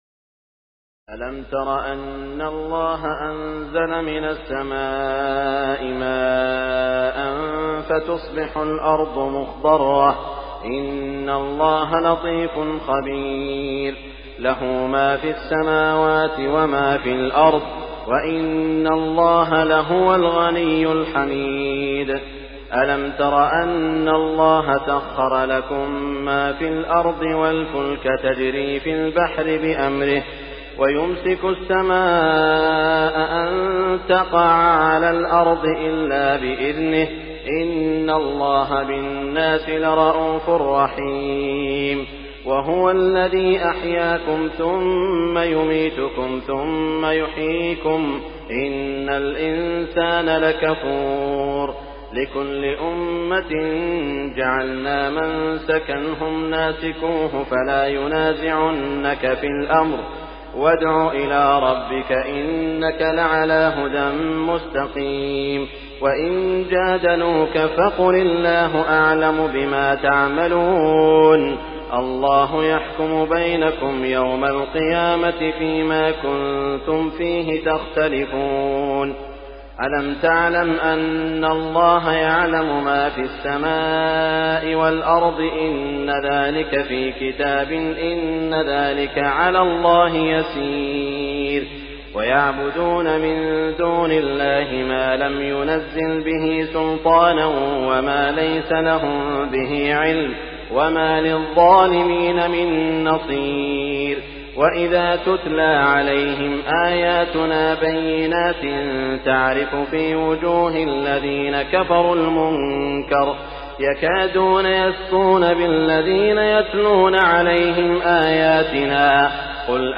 تراويح الليلة السابعة عشر رمضان 1418هـ من سور الحج (63-78) المؤمنون كاملة و النور (1-10) Taraweeh 17 st night Ramadan 1418H from Surah Al-Hajj and Al-Muminoon and An-Noor > تراويح الحرم المكي عام 1418 🕋 > التراويح - تلاوات الحرمين